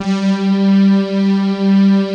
Index of /90_sSampleCDs/Club-50 - Foundations Roland/VOX_xScats_Choir/VOX_xSyn Choir 1